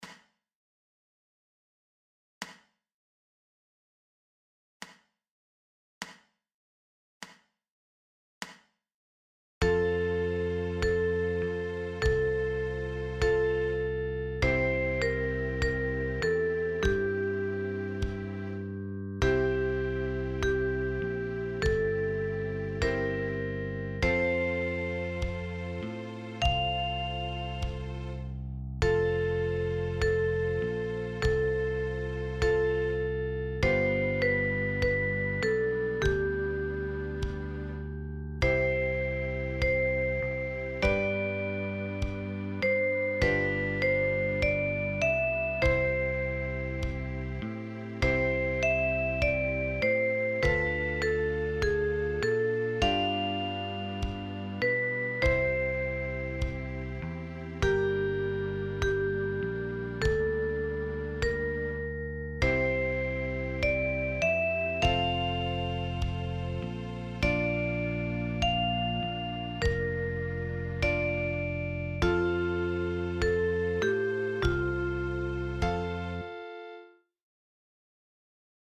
For Alto Recorder in F.